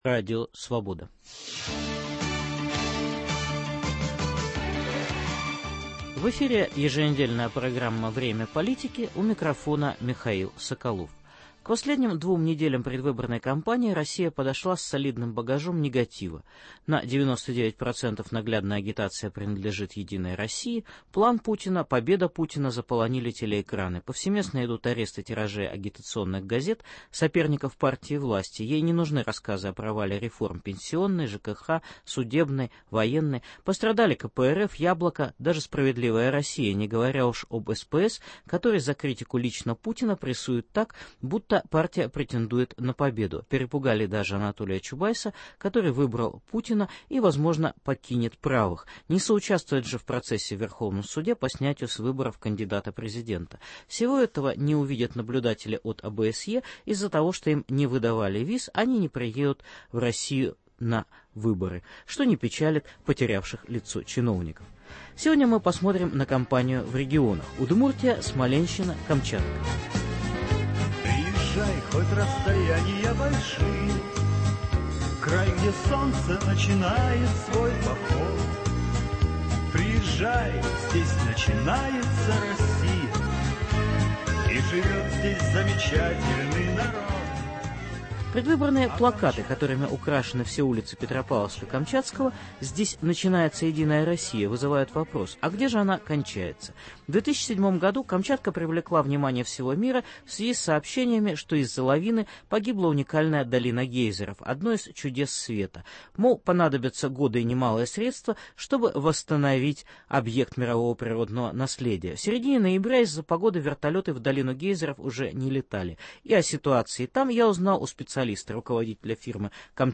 Предвыборные репортажи специальных корреспондентов Радио Свобода